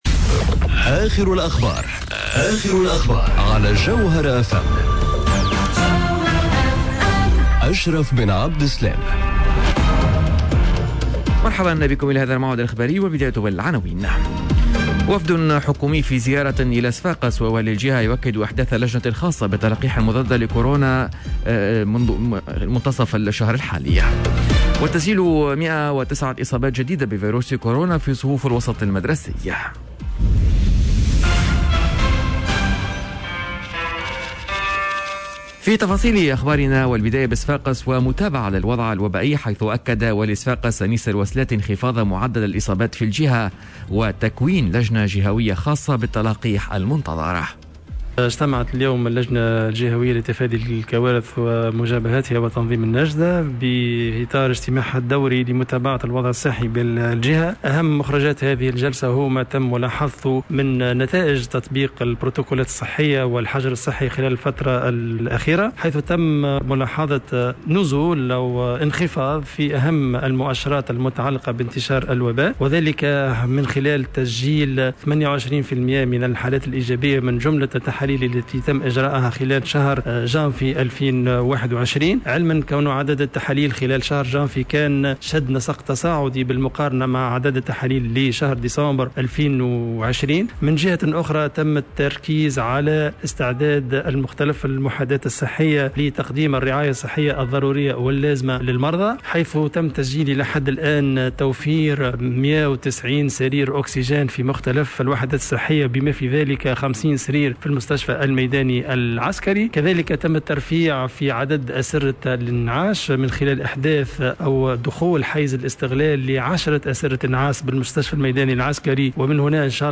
نشرة أخبار منتصف النهار ليوم الإربعاء 03 فيفري 2021